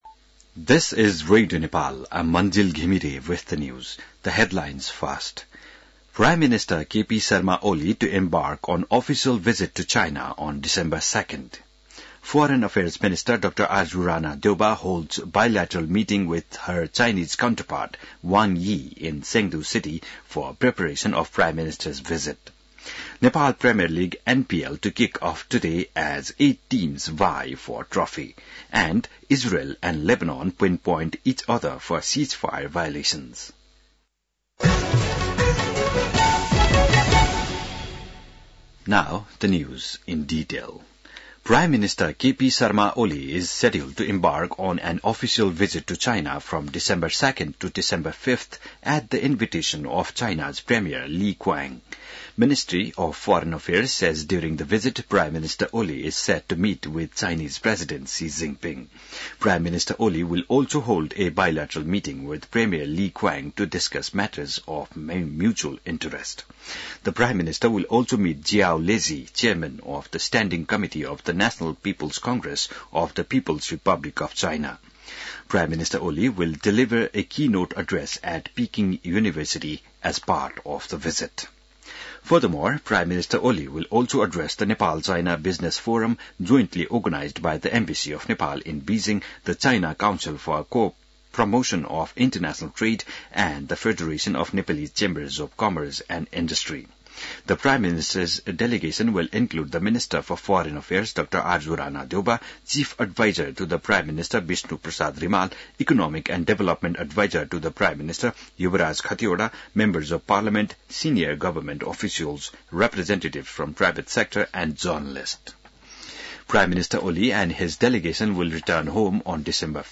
An online outlet of Nepal's national radio broadcaster
बिहान ८ बजेको अङ्ग्रेजी समाचार : १६ मंसिर , २०८१